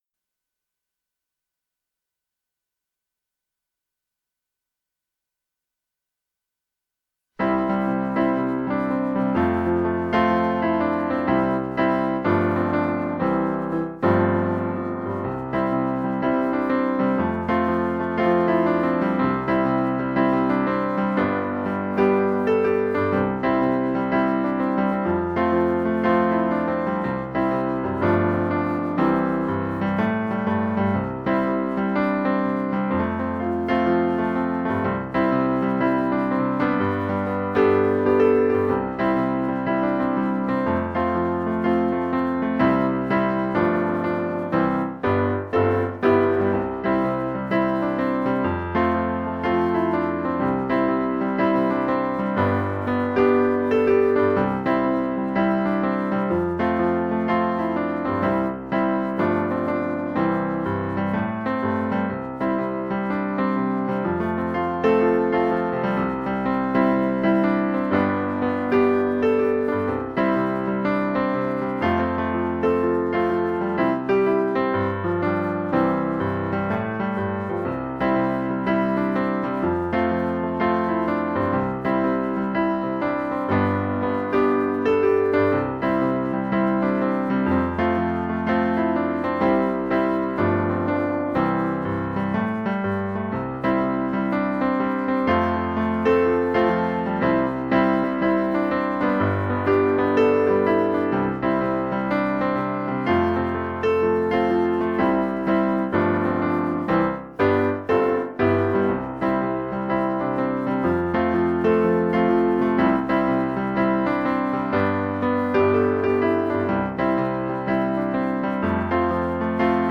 pieseň zo školenia (2015 Vrútky) – noty s akordami, prezentácia a playback
Boh-ťa-miluje-playback1.mp3